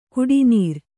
♪ kuḍinīr